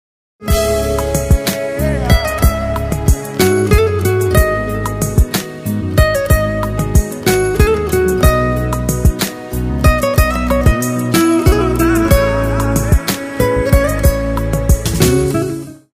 رینگتون احساسی بی کلام